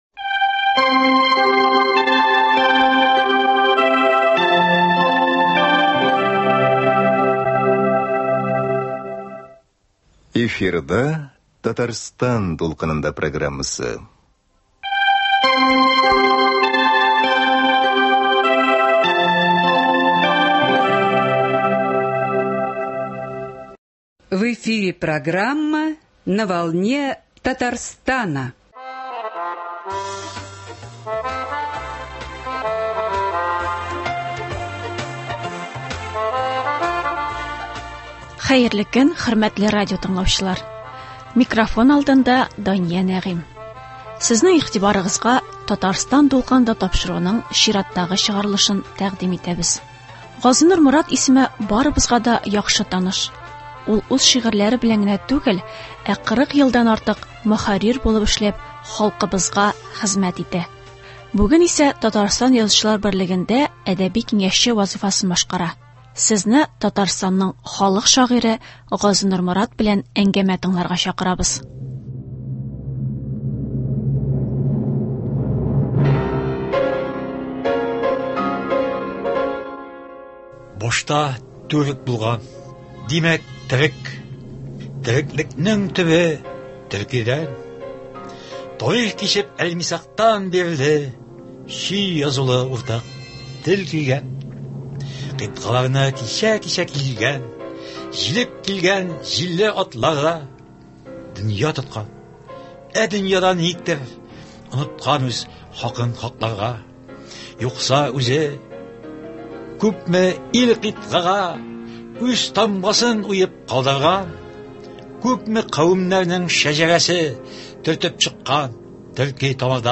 Татарстанның халык шагыйре Газинур Морат белән әңгәмә.
Сезне Татарстанның халык шагыйре Газинур Морат белән әңгәмә һәм аның шигырьләрен үз укуында тыңларга чакырабыз.